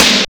LOFI GATESN.wav